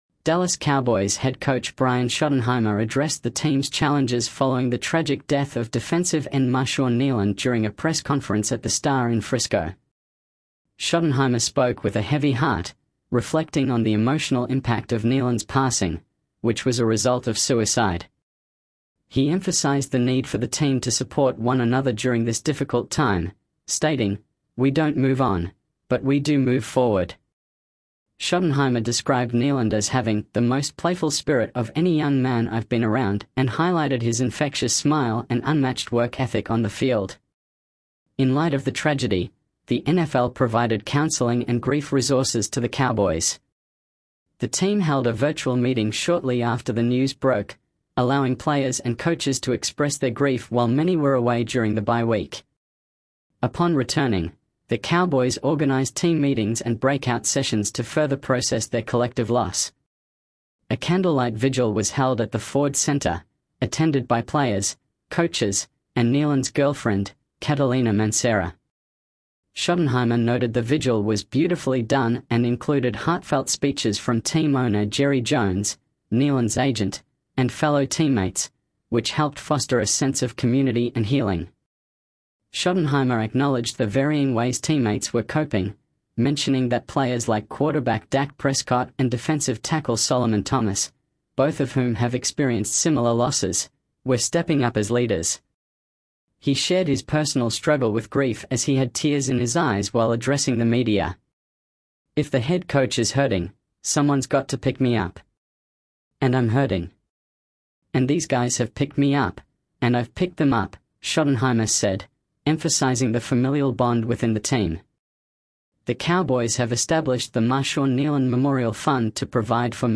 Dallas Cowboys head coach Brian Schottenheimer addressed the team’s challenges following the tragic death of defensive end Marshawn Kneeland during a press conference at The Star in Frisco. Schottenheimer spoke with a heavy heart, reflecting on the emotional impact of Kneeland’s passing, which was a result of suicide. He emphasized the need for the team to support one another during this difficult time, stating, "We don’t move on, but we do move forward."